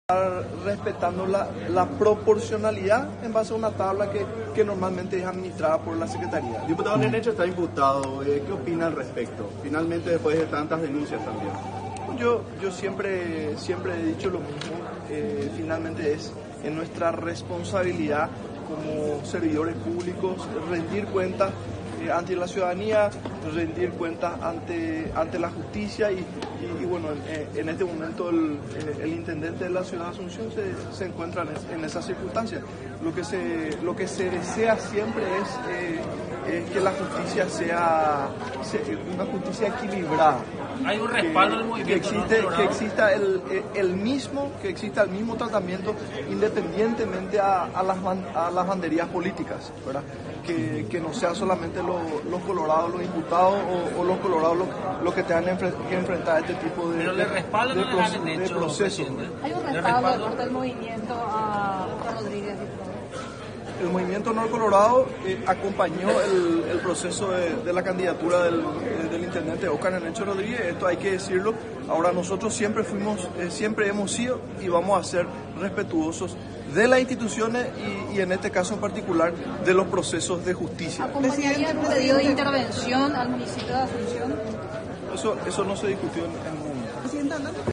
Latorre, quien también es presidente de la Cámara de Diputados, subrayó ante la prensa que Honor Colorado respaldó la candidatura de Rodríguez en su momento, pero que este apoyo no exime al intendente de rendir cuentas ante la justicia.